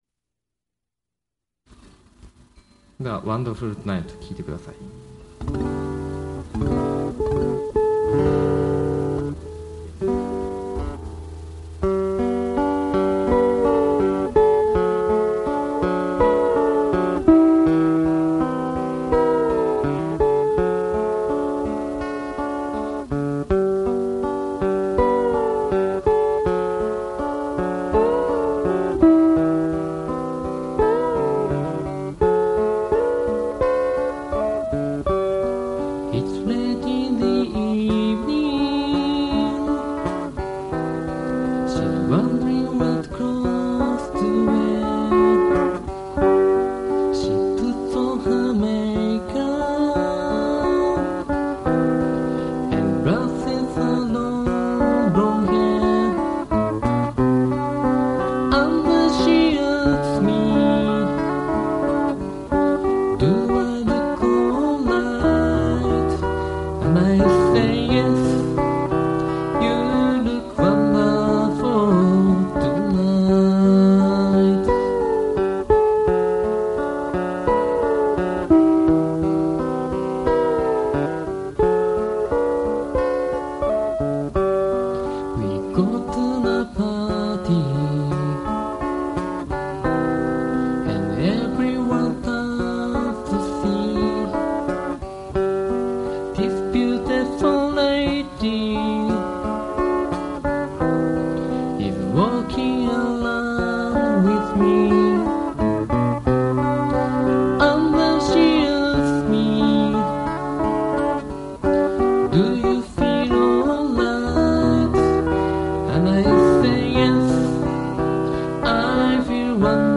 Vocal,A.guitar
E.guitar